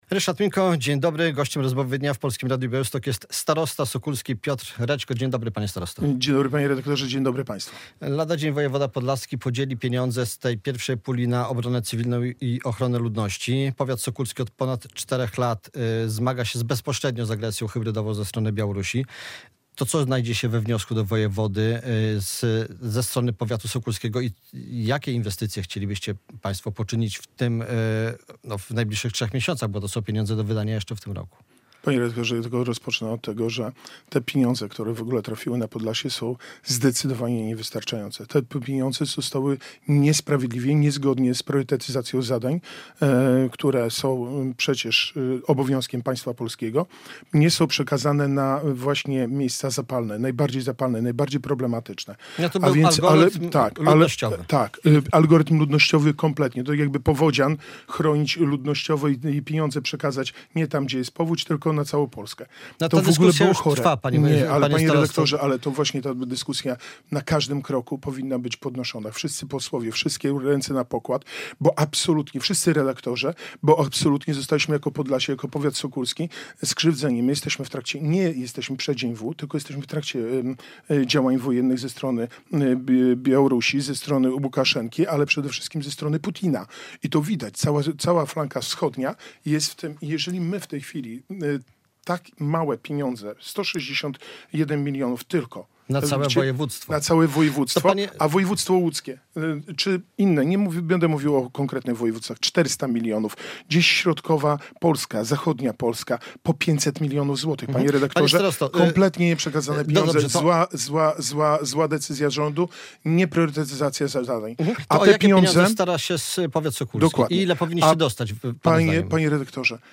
Podlasie zostało skrzywdzone - pieniądze rozdzielono nie tam, gdzie faktycznie jest zagrożenie - podkreśla starosta sokólski Piotr Rećko, krytykując podział pieniędzy na obronę cywilną.
Radio Białystok | Gość | Piotr Rećko [wideo] - starosta powiatu sokólskiego